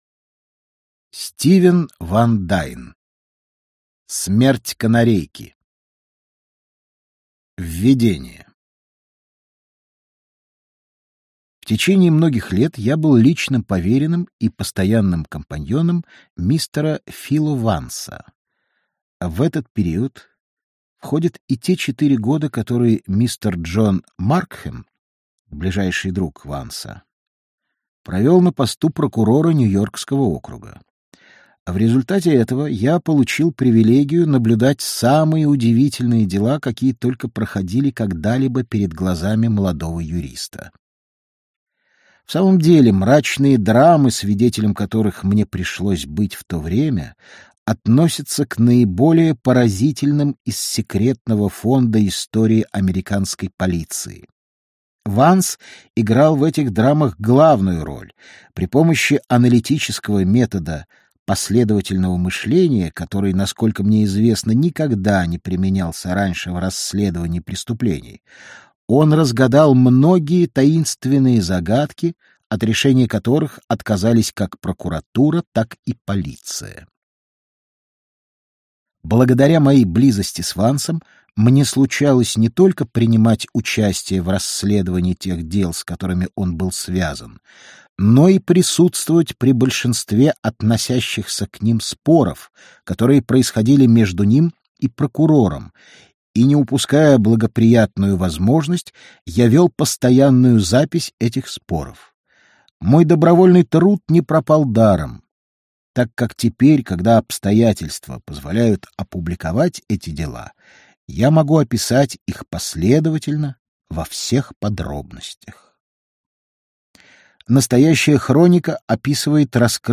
Аудиокнига Смерть канарейки | Библиотека аудиокниг